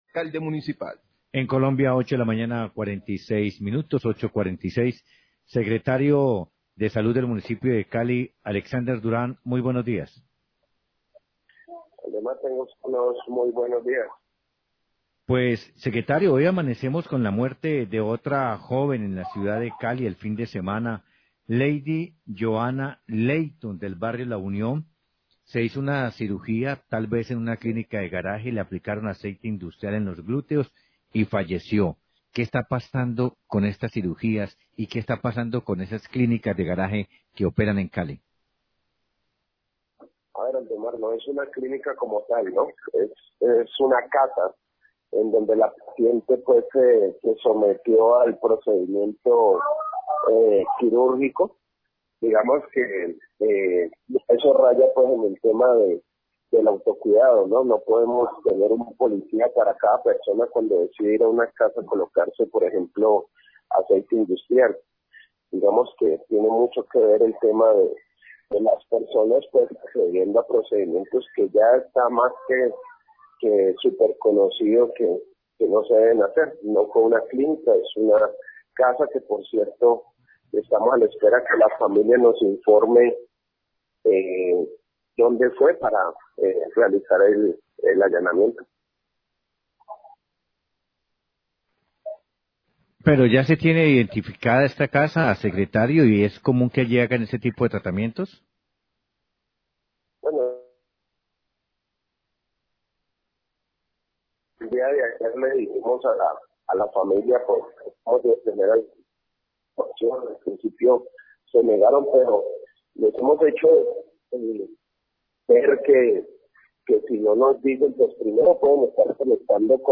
Radio
El secretario de Salud, Alexander Durán, habló sobre el caso de una mujer que murió luego de someterse a una cirugia estética, al parecer en una casa. Indicó que este caso se encuentra en investigación para establecer el lugar donde se realizó la cirugía para proceder a realizar el allanamiento con la fiscalía.